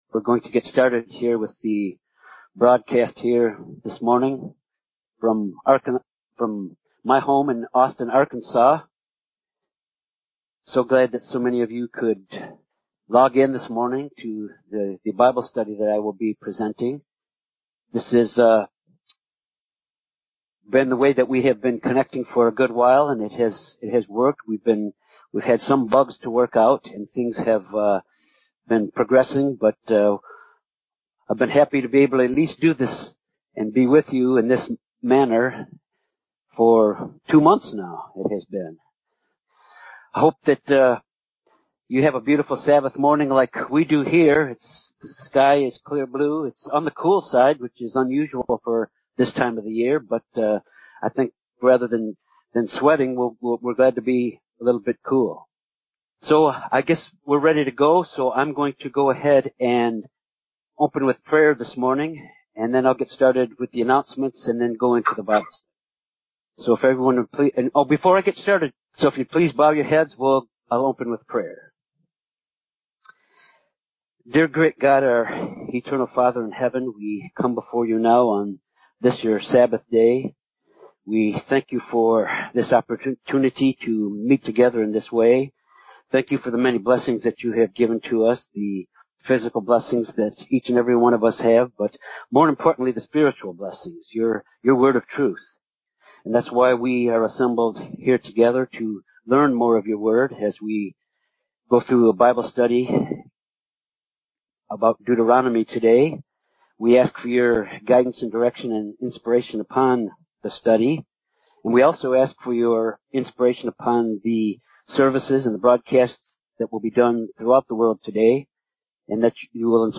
This Bible study covers the conclusion of the 1st “sermon” Moses gave the new generation of Israelites in a historical review of Israel’s journey out of Egypt into the promised land and how God had always been in control and the lessons they had learned during their travels. Also covered is the renewal of the of their covenant with God prior to crossing Jordan into the Promised Land.